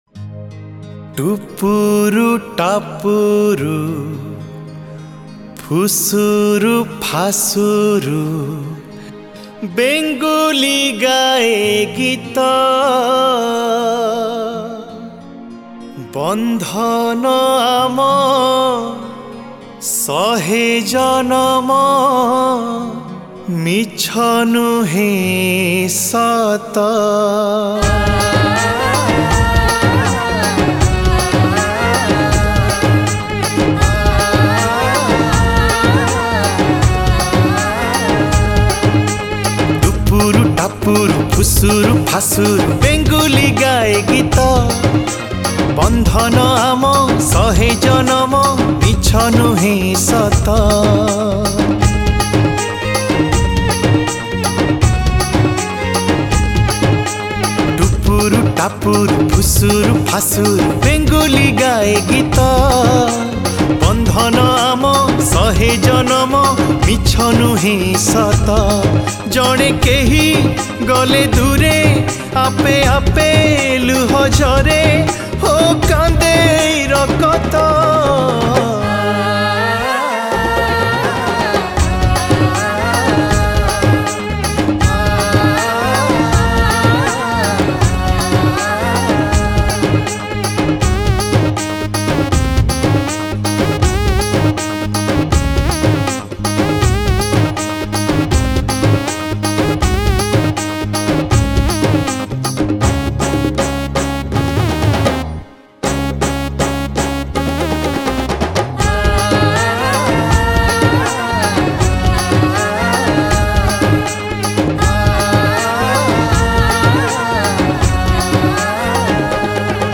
Odia Jatra Song Songs Download